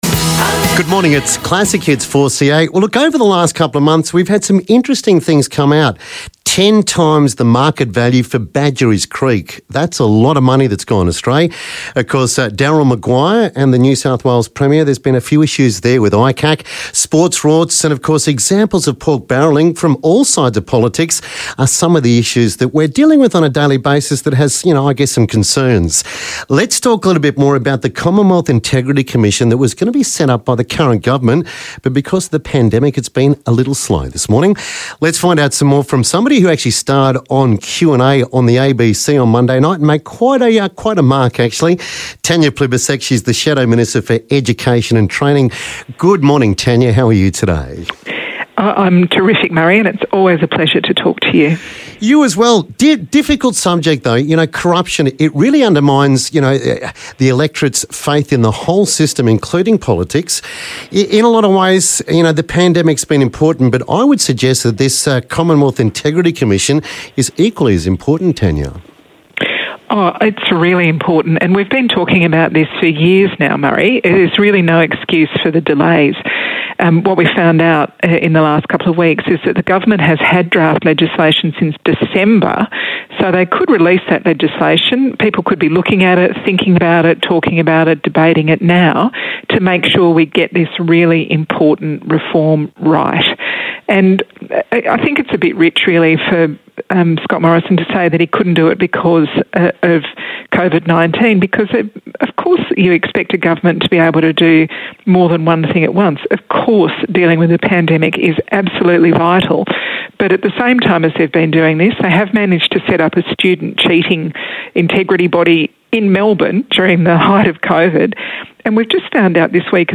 speaks with Labor Frontbencher Tanya Plibersek about the Morrison Government proposed anti corruption watchdog